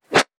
metahunt/weapon_bullet_flyby_16.wav at master
weapon_bullet_flyby_16.wav